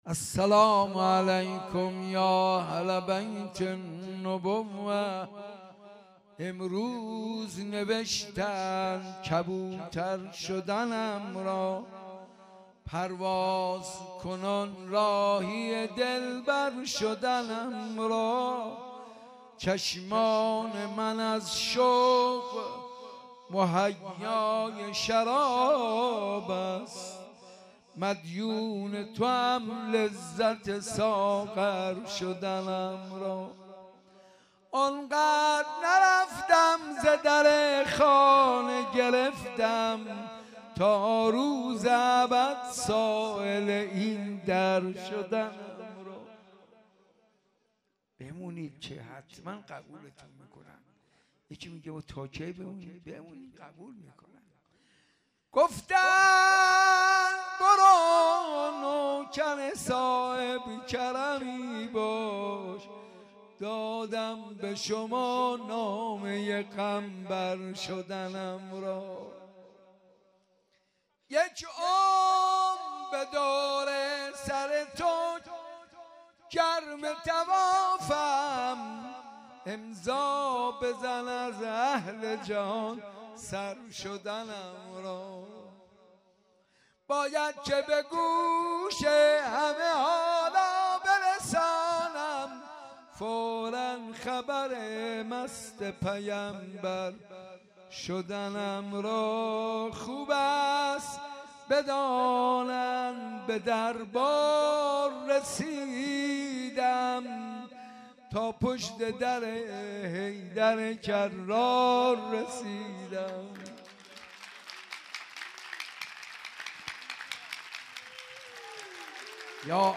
جشن میلاد حضرت رسول و امام صادق(ع)-حسینیه بیت الزهرا(س)